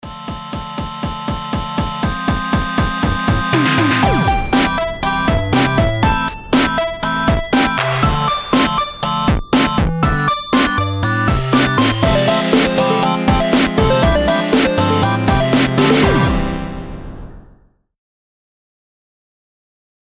1. i used to cringe every time i’d hear the theme i’d written for you guys but now that you’ve been playing it slowed down, i’m about to lose my mind! maybe you could use the 20 sec bumper instrumental i did a while back?
videogametheme.mp3